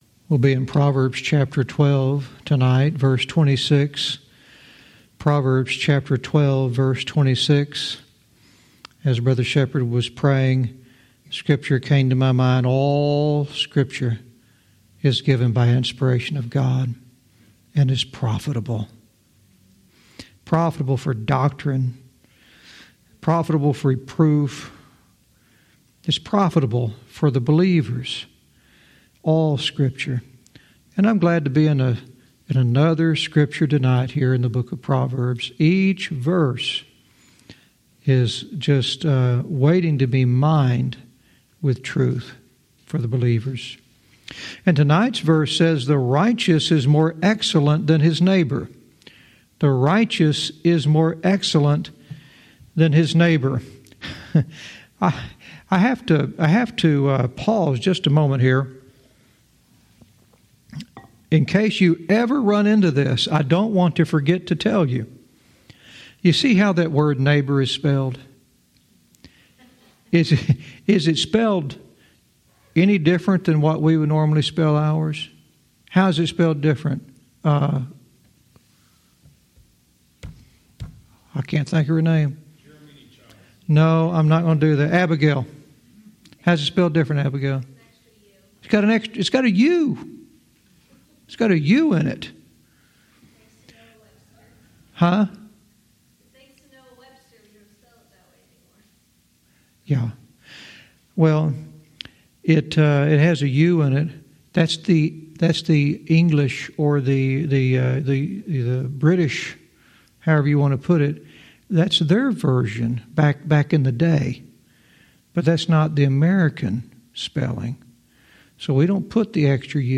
Verse by verse teaching - Proverbs 12:26 "A Curve in the Road"